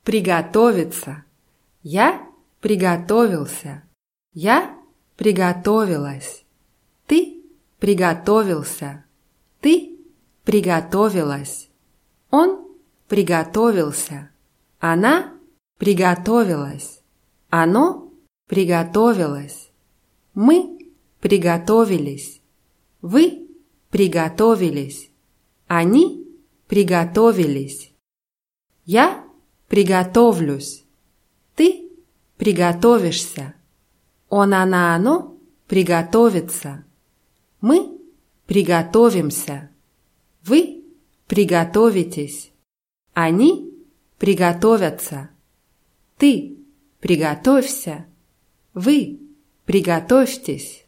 приготовиться [prʲigatówʲitsa]